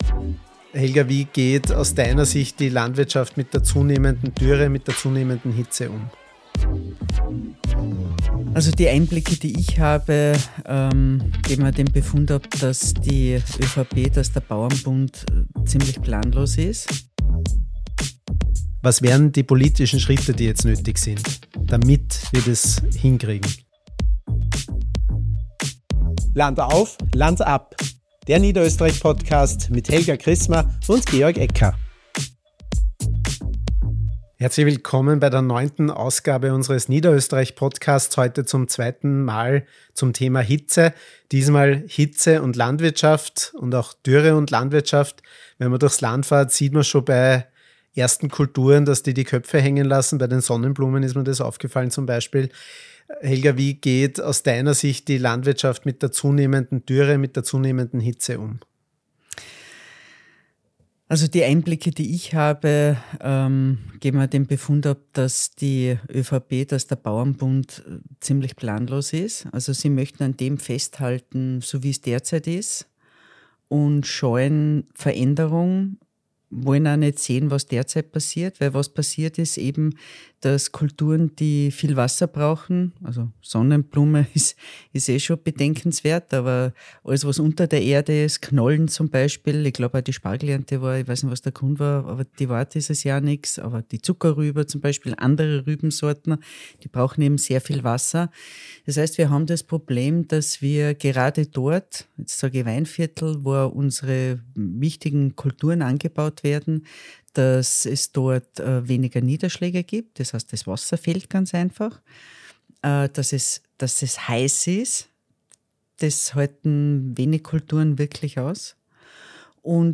Klubobfrau Helga Krismer und LAbg. Georg Ecker analysieren, warum alte Rezepte wie Pestizide und ein „Weiter wie bisher“ nicht mehr funktionieren und welche innovativen Ansätze Hoffnung machen: Von klugen Fruchtfolgen und Zisternen über die Rückkehr zu Hecken und Agroforst bis hin zu politischen Weichenstellungen, die wirklich etwas bewegen. Wir sprechen über die Schwammfunktion gesunder Böden, die Bedeutung von Wasser in der Region und warum es mutige Vorbilder braucht, die zeigen, dass nachhaltige Landwirtschaft möglich ist.